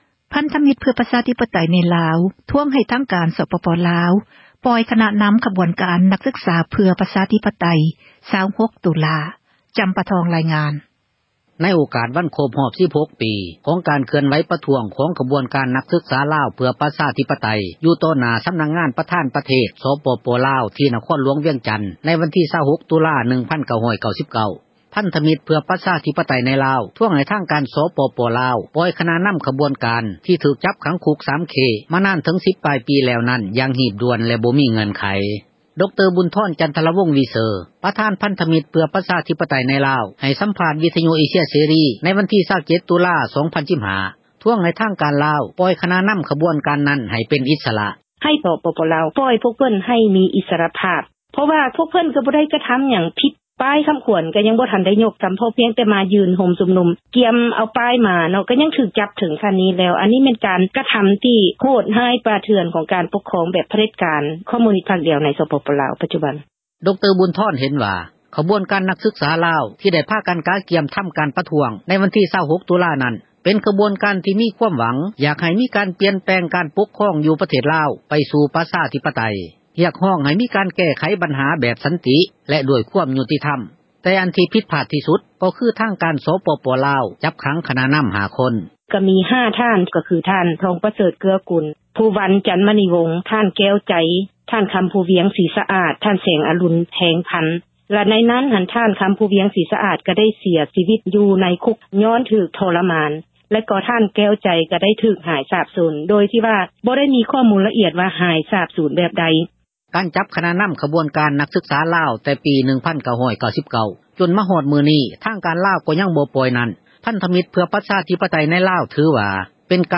ໃຫ້ ສຳພາດ ວິທຍຸ ເອເຊັຽ ເສຣີ ຜແນກ ພາສາລາວ